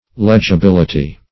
Legibility \Leg`i*bil"i*ty\ (l[e^]j`[i^]*b[i^]l"[i^]*t[y^]), n.